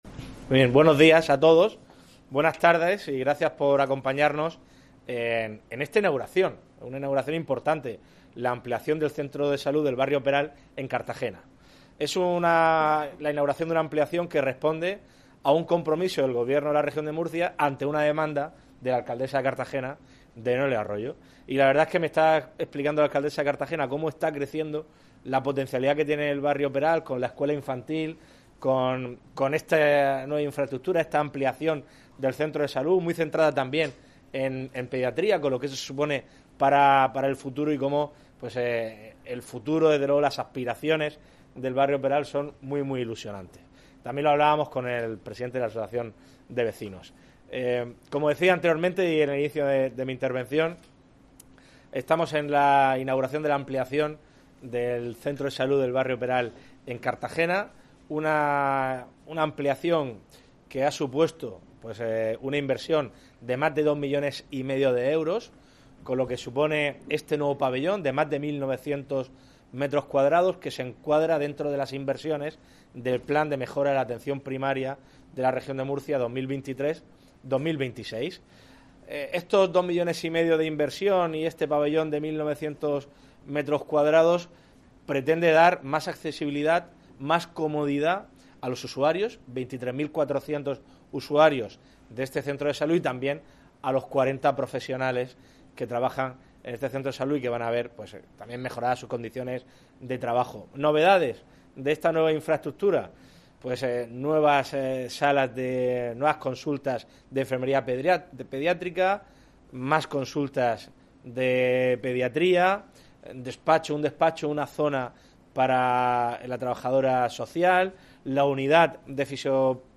Enlace a Declaraciones del presidente regional Fernando López y la alcaldesa de Cartagena, Noelia Arroyo, en la inauguración del centro de salud de Barrio de Isaac Peral.